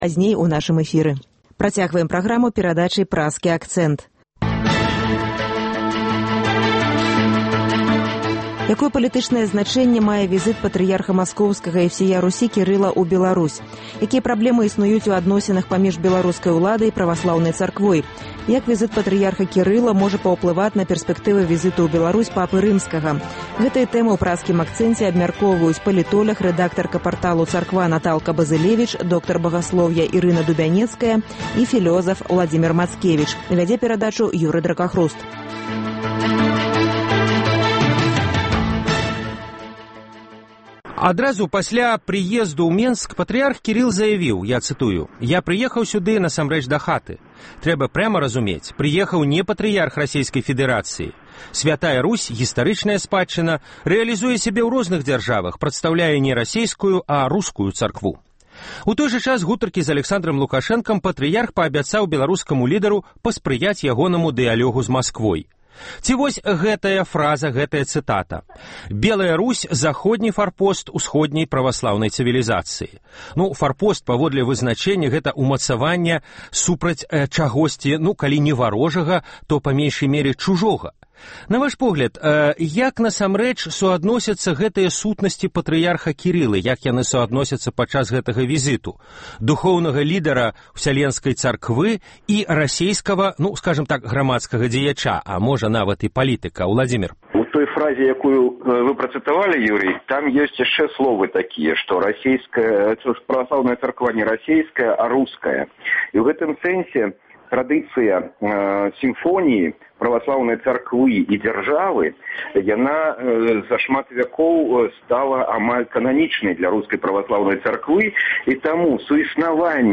Гэтыя тэмы ў “Праскім акцэнце” абмяркоўваюць : палітоляг